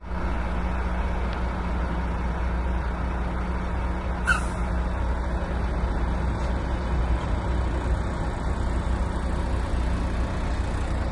缝制2
描述：缝纫机现场录音
Tag: 机械 机械 机器 缝纫 工业 MACHINE